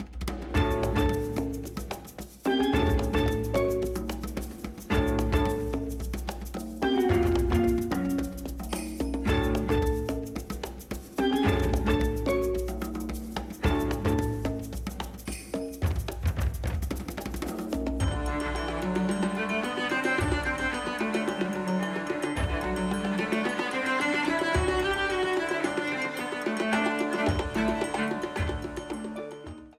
Ripped from the game
clipped to 30 seconds and applied fade-out
Fair use music sample